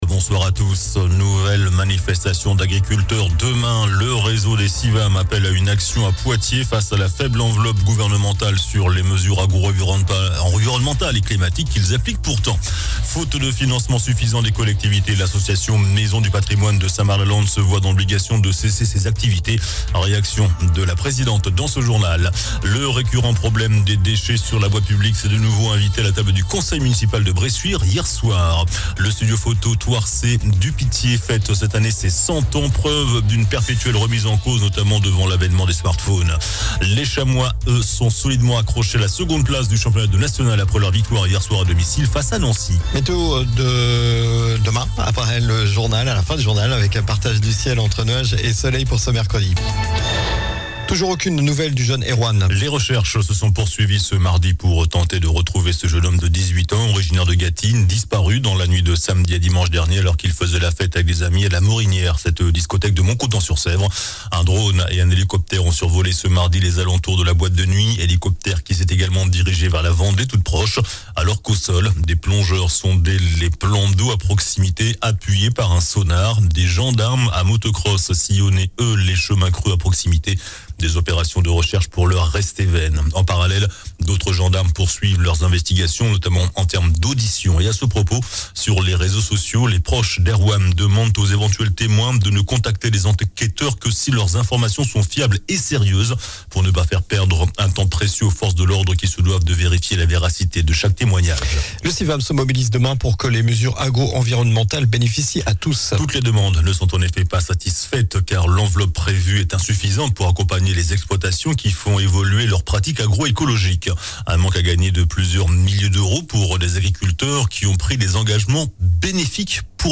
JOURNAL DU MARDI 13 FEVRIER ( SOIR )